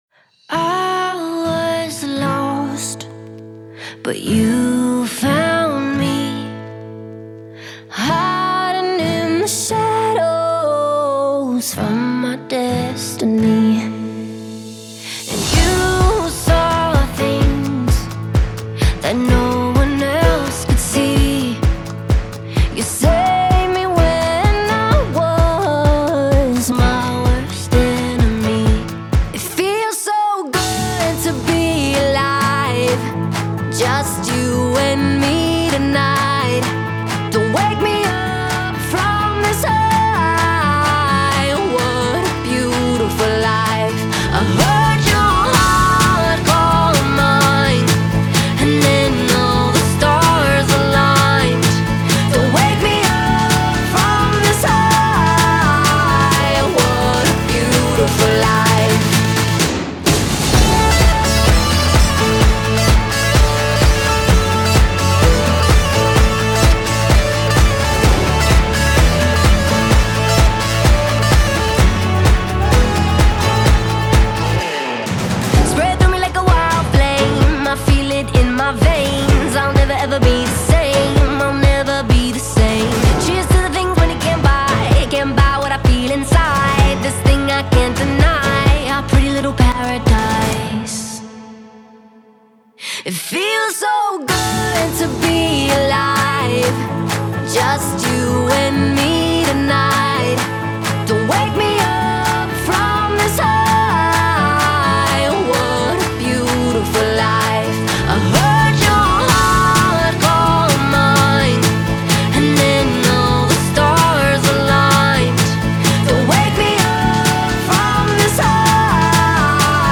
это вдохновляющая поп-песня
Звучание наполнено энергией